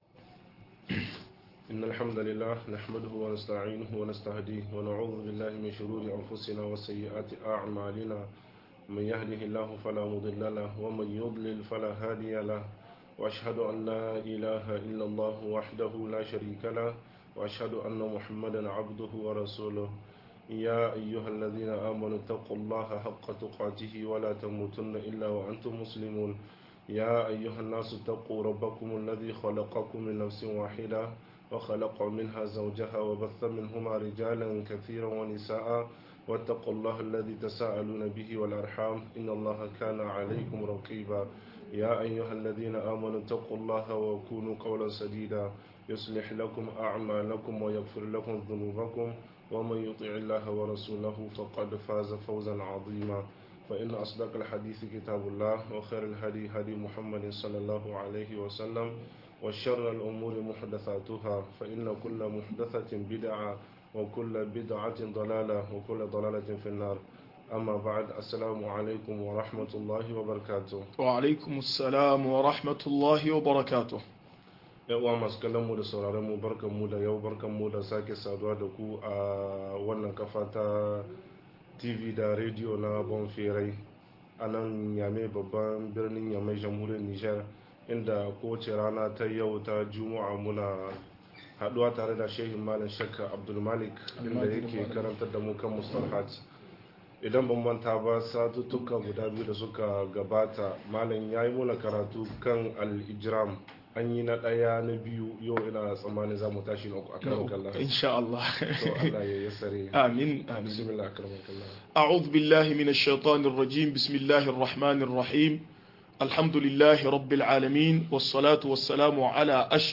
Miyagun Laifuka-03 - MUHADARA